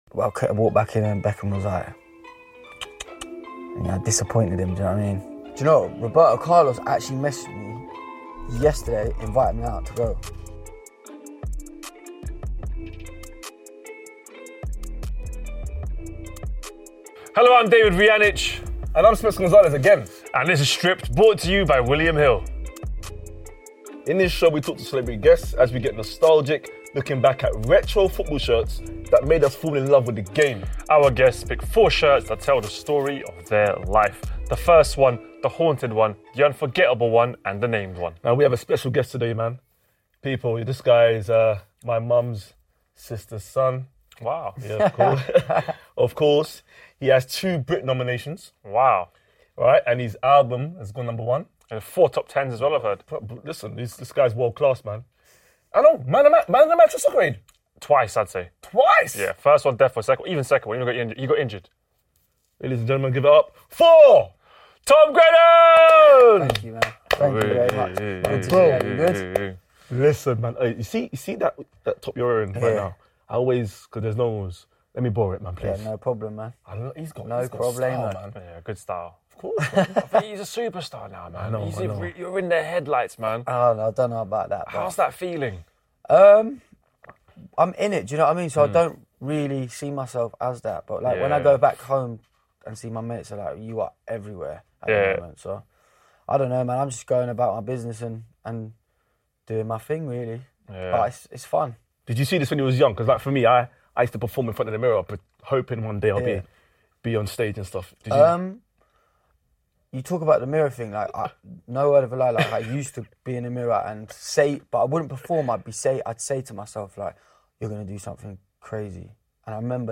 We sit down with chart-topping singer and soccer-aid star Tom Grennan.